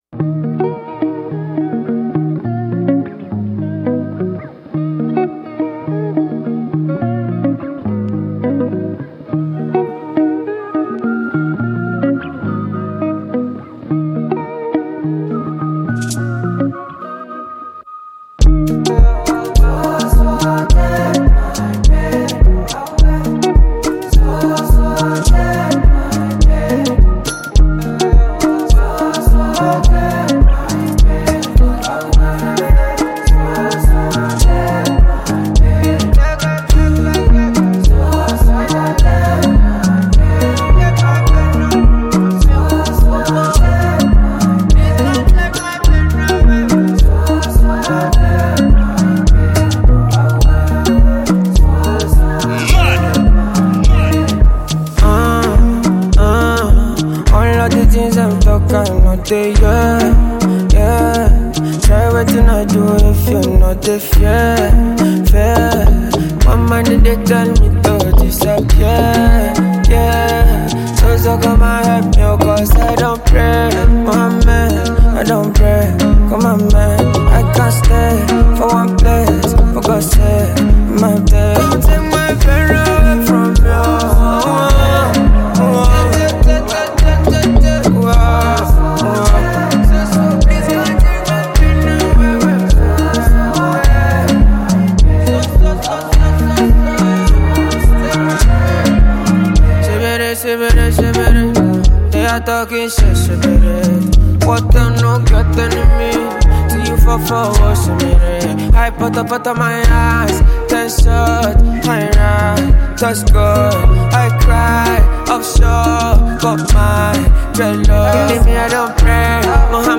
smooth vocals, the catchy hooks
blends Afrobeat with contemporary sounds
With its high energy tempo and catchy sounds